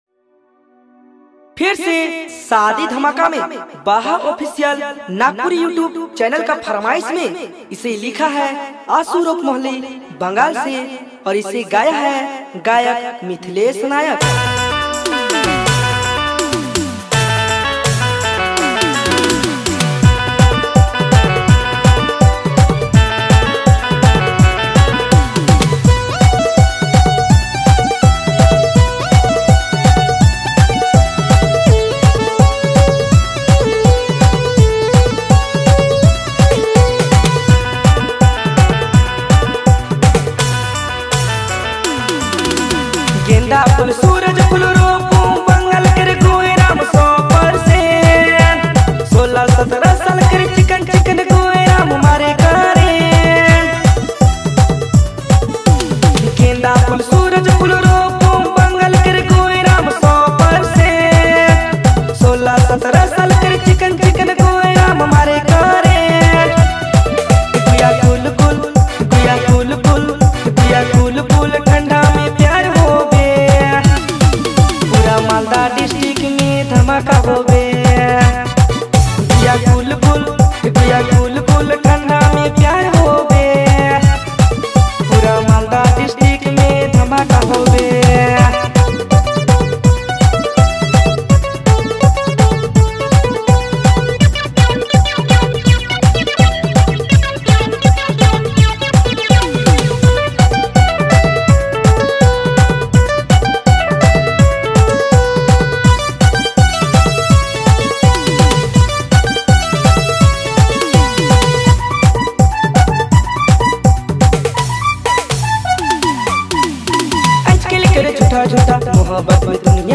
All Dj Remix